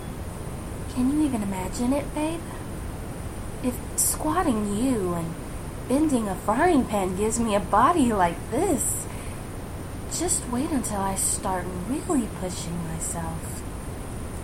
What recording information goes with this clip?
Please note, I cannot eliminate all of the background noise, but this is an option for those of you with more sensitive ears.